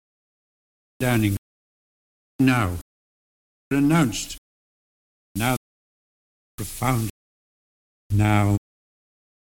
All the recordings have been subjected to lossy MP3 compression at some time during their lives.
Listen to the [au]-like MOUTH by Neville Chamberlain (Figure 3):